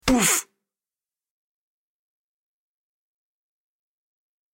دانلود آهنگ تصادف 34 از افکت صوتی حمل و نقل
دانلود صدای تصادف 34 از ساعد نیوز با لینک مستقیم و کیفیت بالا
جلوه های صوتی